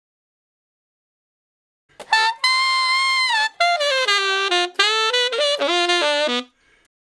066 Ten Sax Straight (D) 28.wav